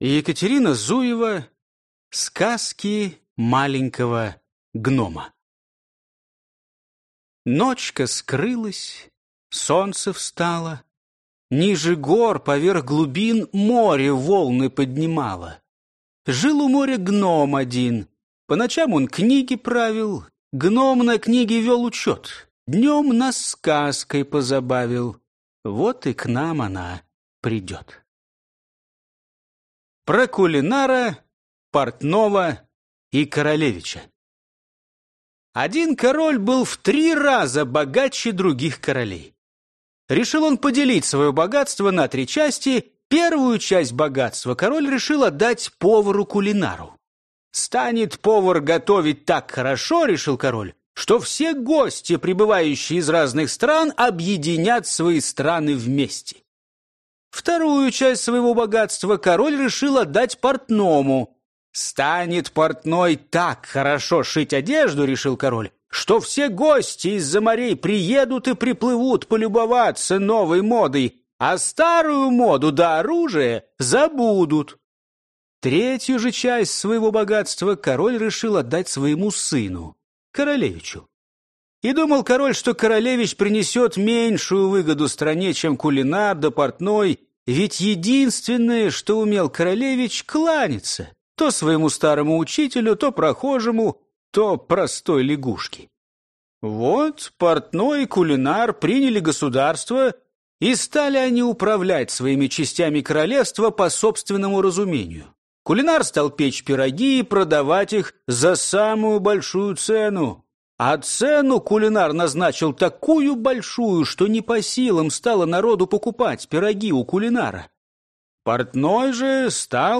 Аудиокнига Сказки Маленького гнома. Приключения | Библиотека аудиокниг